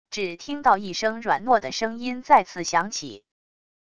只听到一声软糯的声音再次响起wav音频生成系统WAV Audio Player